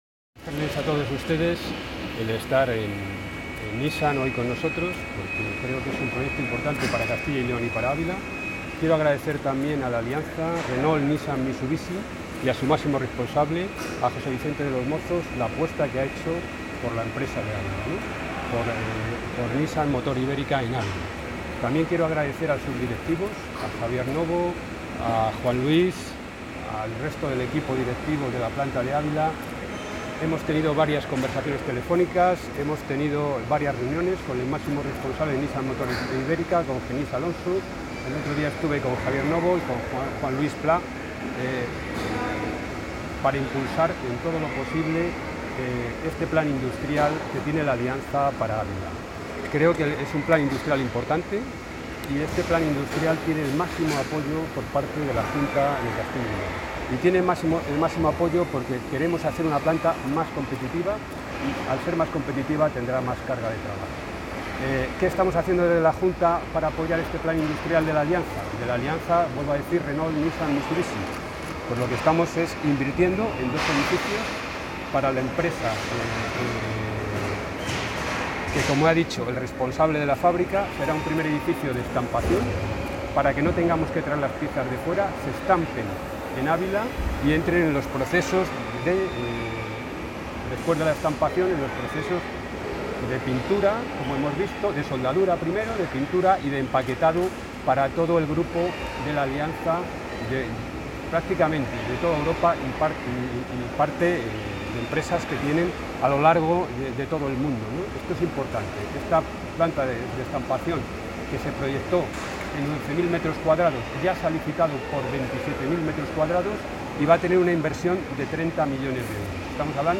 Audio consejero.